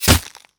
bullet_impact_ice_01.wav